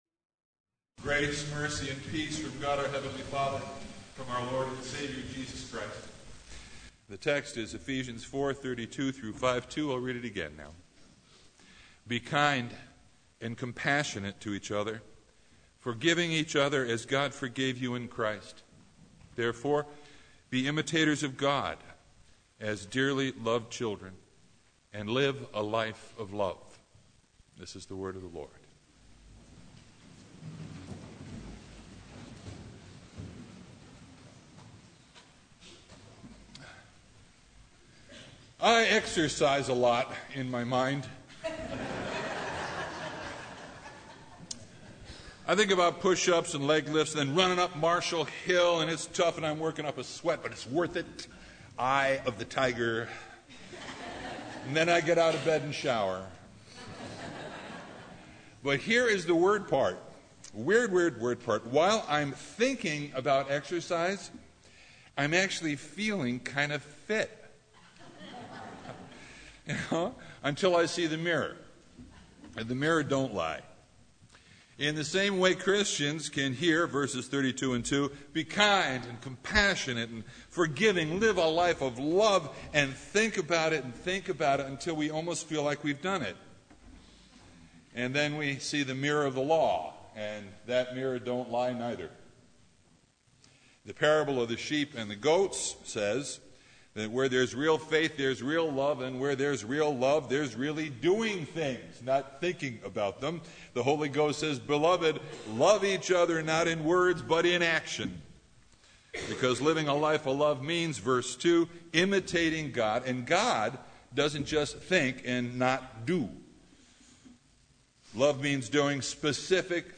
Service Type: Sunday
Sermon Only